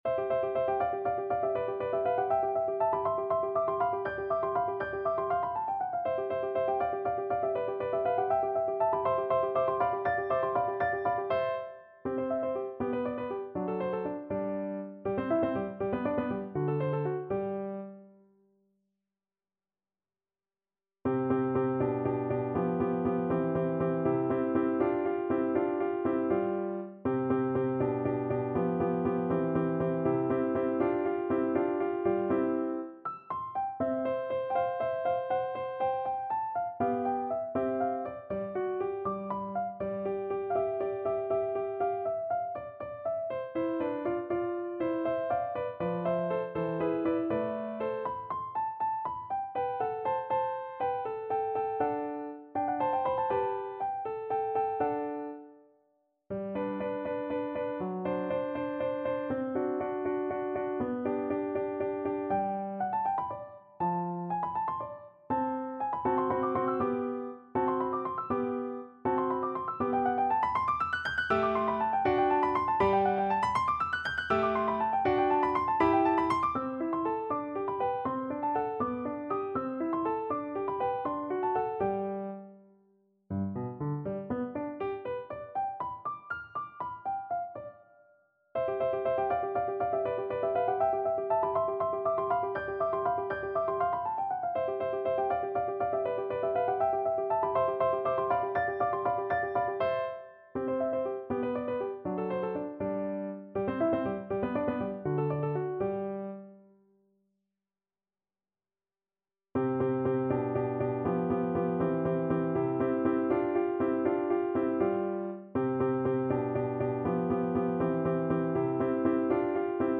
~ = 100 Allegro (View more music marked Allegro)
6/8 (View more 6/8 Music)
Classical (View more Classical Bassoon Music)